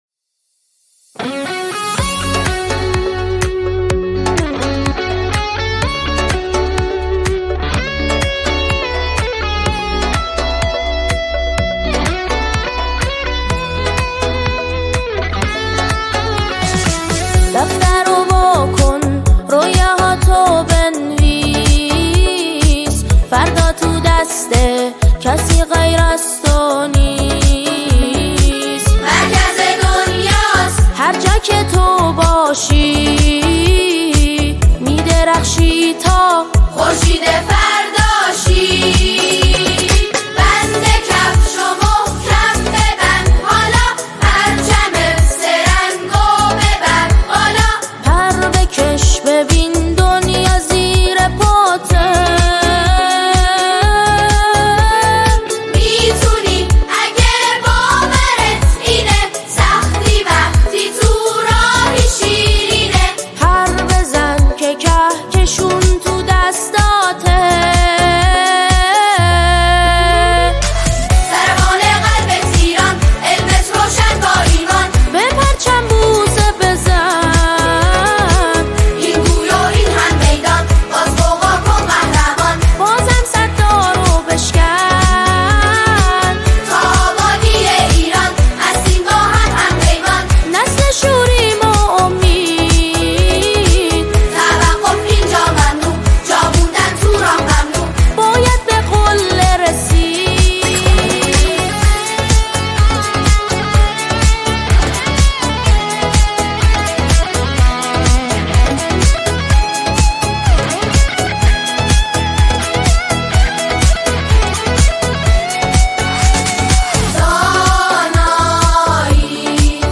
اثری انگیزشی و امیدآفرین است
ژانر: سرود